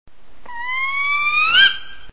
spotted2 Female contact call
spotted2-female-contact-call